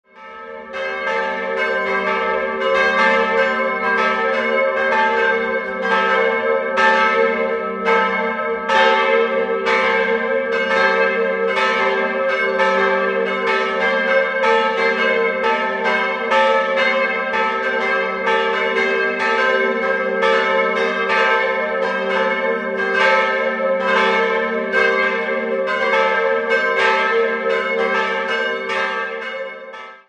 Es lohnt sich, einmal kurz anzuhalten und die im Jahr 1735 über älteren Grundmauern neu errichtete barocke Pfarrkirche mit dem wohlgeformten Zwiebelturm zu besichtigen. 3-stimmiges Gloria-Geläute: g'-a'-c'' Nähere Daten liegen nicht vor.